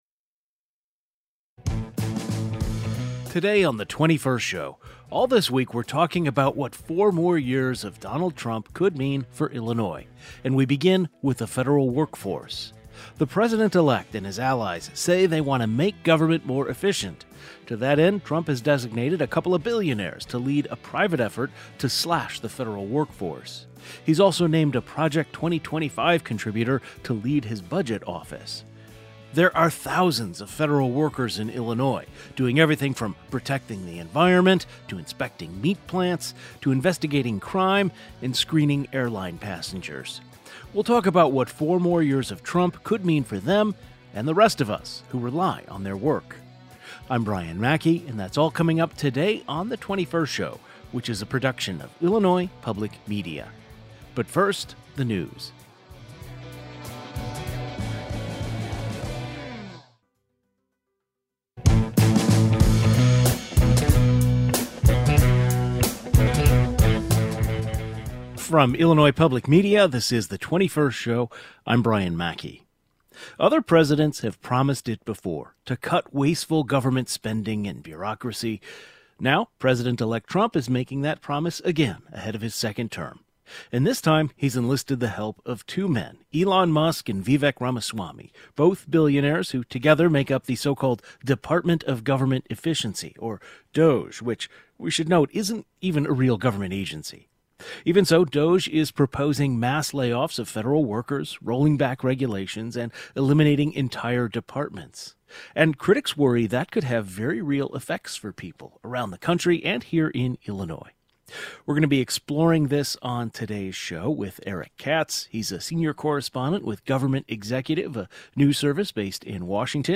A panel of experts on goverment employees and policies related to this join today's program to analyze these potential effects.